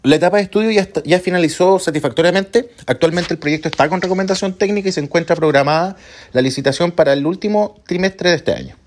En ese sentido, el actual seremi de Obras Públicas, Daniel Olhabé, confirmó los plazos establecidos para contar con una nueva infraestructura.